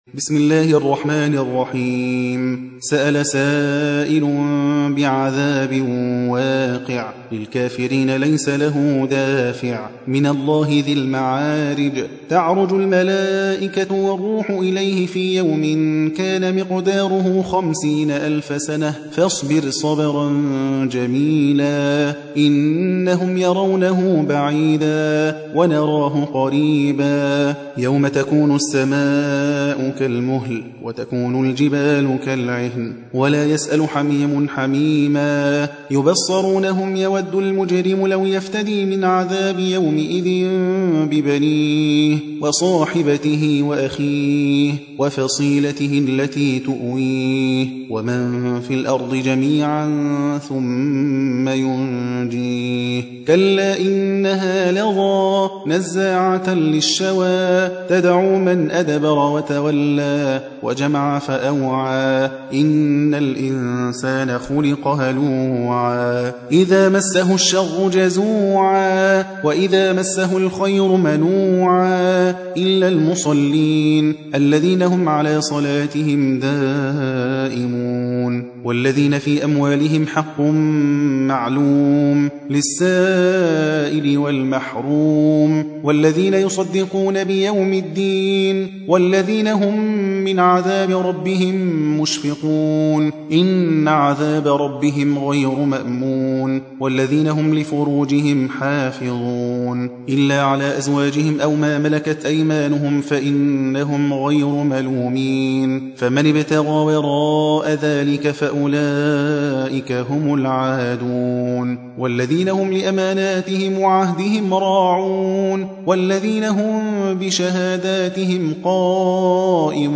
70. سورة المعارج / القارئ